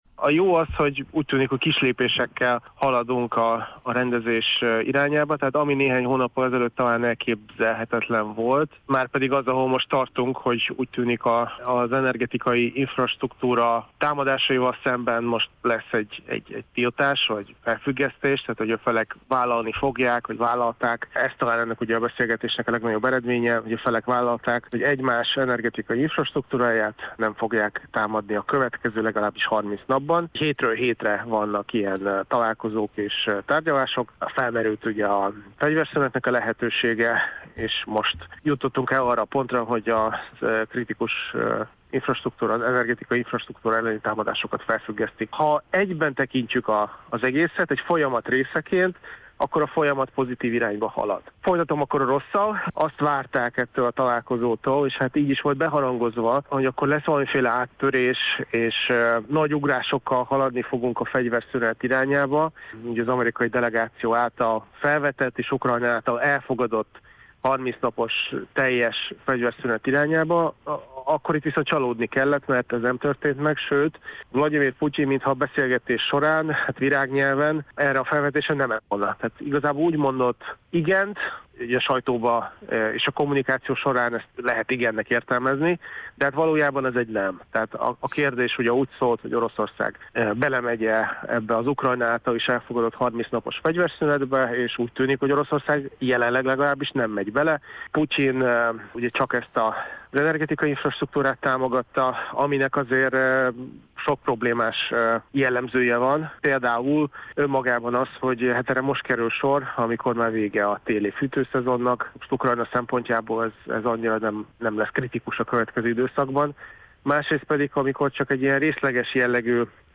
külpolitikai elemzőt kérdezte